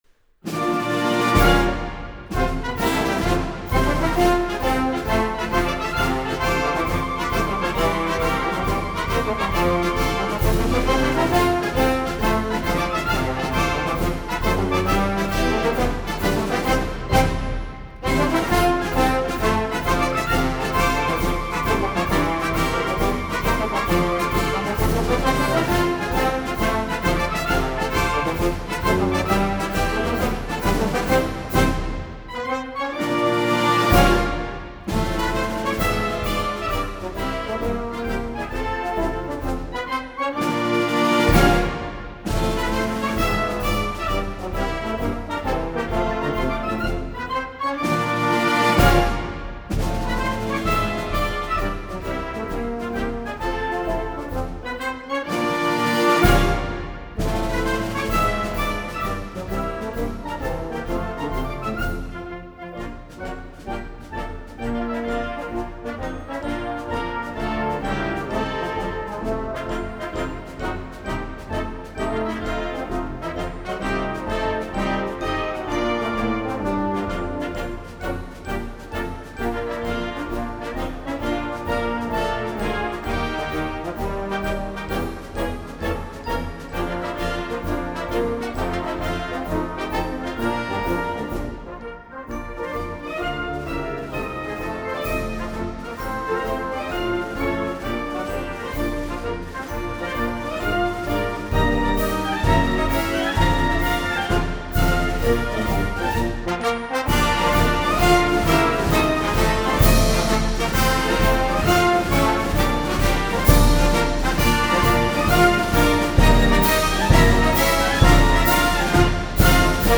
这是一张不吵的进行曲录音，它虽然没有办法换下您家中的1812炮声，